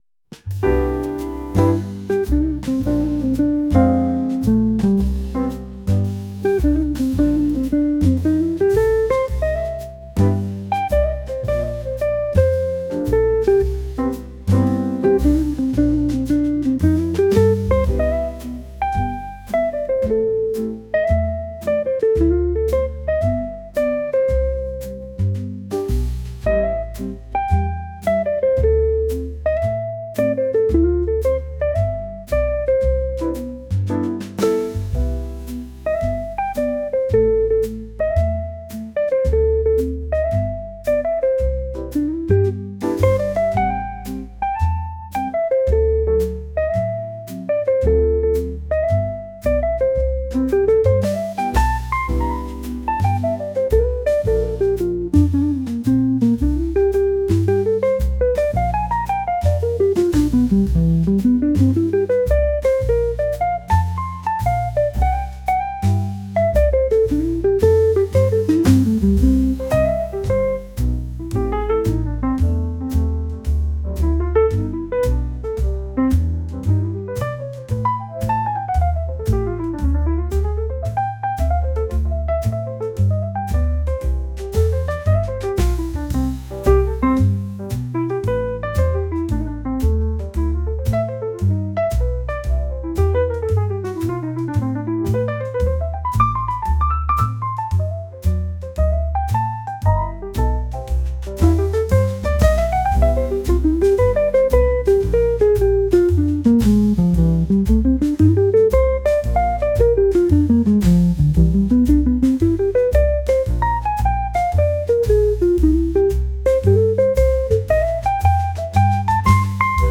ジャンルJAZZ
楽曲イメージChill, Lo-Fi, ゆったり, カフェ, 日常, , 爽やか
シーン店舗BGM, 雑談